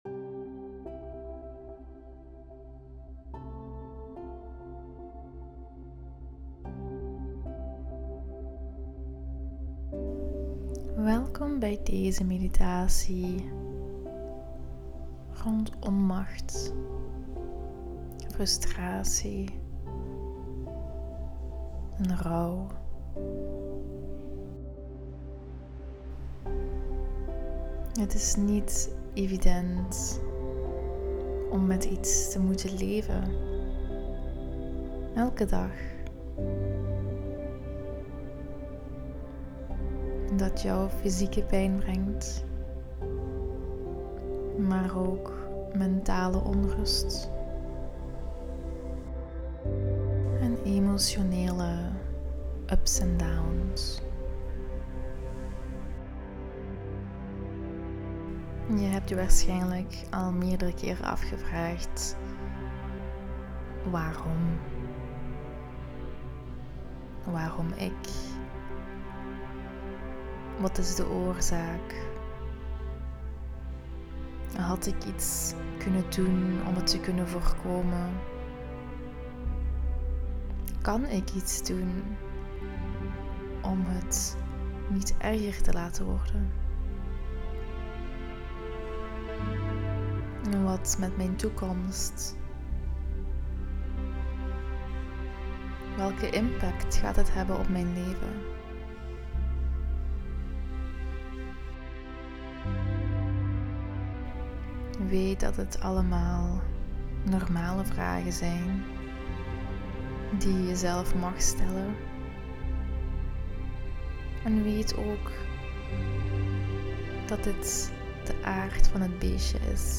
Onmacht_frustratie_droevigheid_meditatie-1.mp3